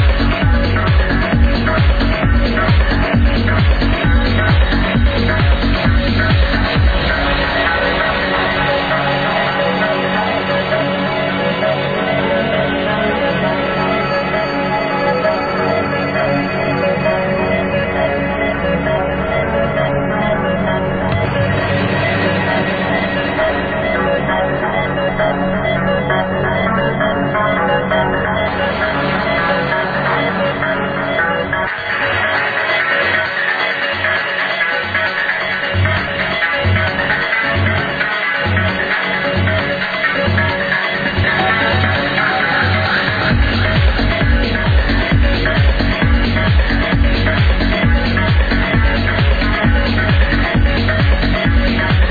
Arrow Prog Track